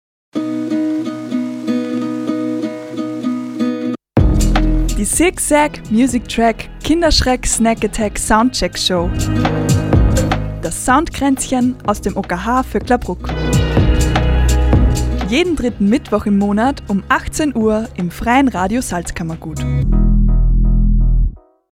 Sendungstrailer
FRS-TRAILER-SOUNDKRAeNZCHEN-JEDEN-3-MITTWOCH-IM-MONAT.mp3